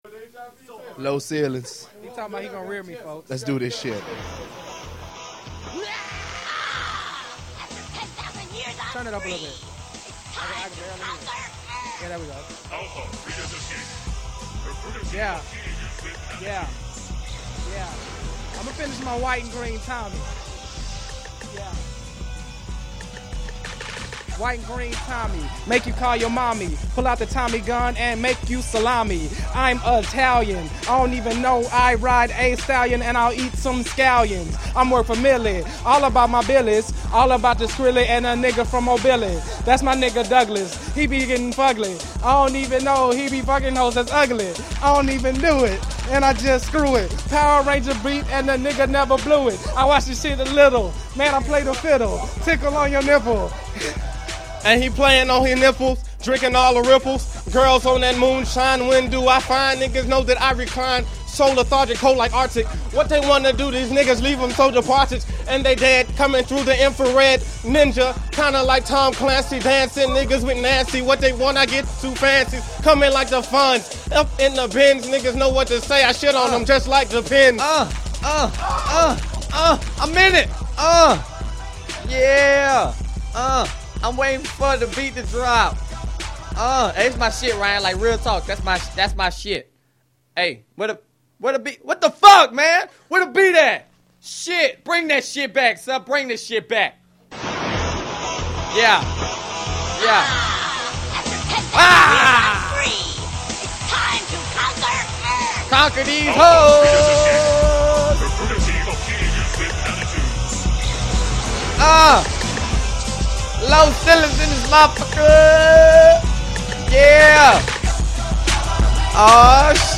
after tirelessly pouring over Audacity and looped midi files